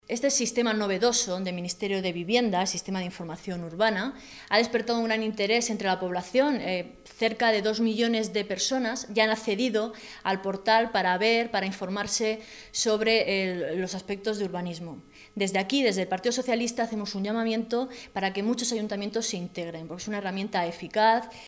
Marta Abarca, diputada regional del PSOE de Castilla-La Mancha
Cortes de audio de la rueda de prensa